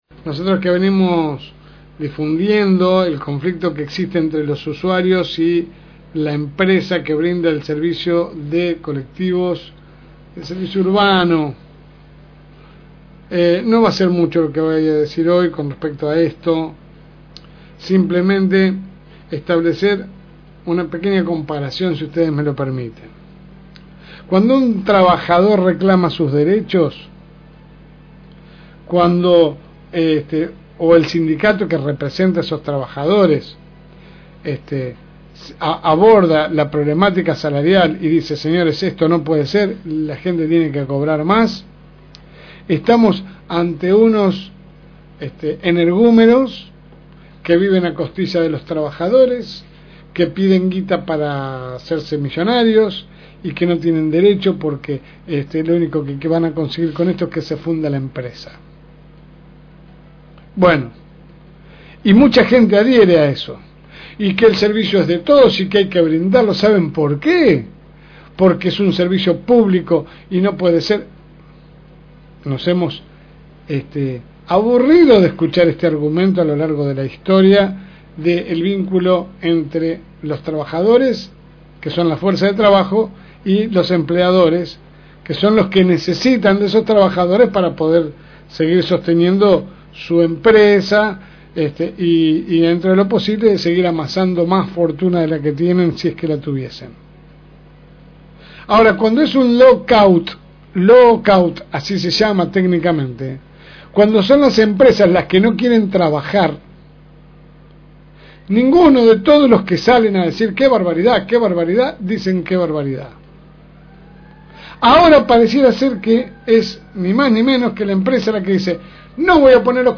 AUDIO – Editorial de la LSM.